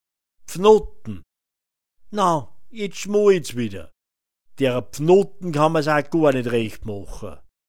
Pfnottn [‚pfnotn] f
Hörbeispiel „Pfnottn“